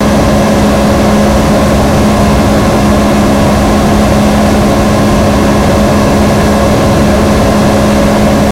superb-warfare/src/main/resources/assets/superbwarfare/sounds/speedboat/engine.ogg at feafd5a54df288cf6988d611c15fc288e39a3cfb
engine.ogg